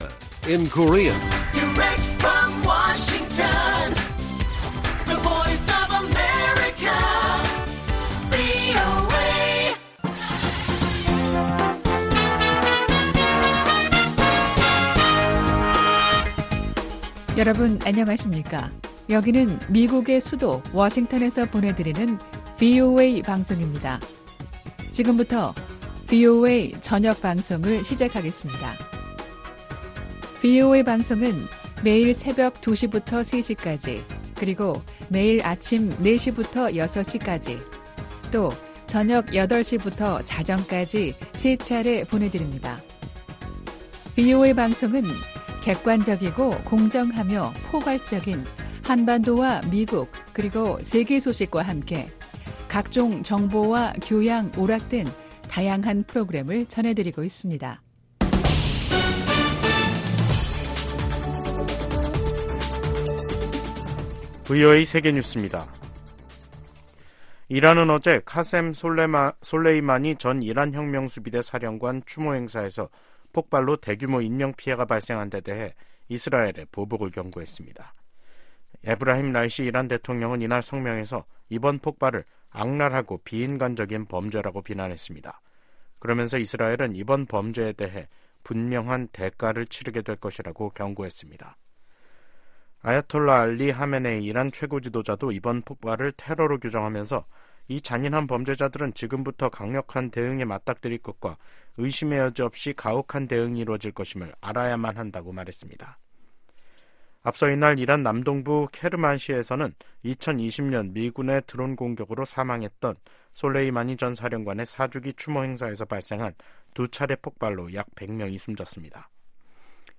VOA 한국어 간판 뉴스 프로그램 '뉴스 투데이', 2024년 1월 4일 1부 방송입니다. 미국은 북한과의 대화에 큰 기대는 않지만 여전히 환영할 것이라고 국무부 대변인이 말했습니다. 김정은 북한 국무위원장이 남북관계를 '적대적 두 국가 관계'로 선언한 이후 북한은 대남노선의 전환을 시사하는 조치들에 나섰습니다. 23일로 예정된 중국에 대한 유엔의 보편적 정례인권검토(UPR)를 앞두고 탈북민 강제북송 중단 압박이 커지고 있습니다.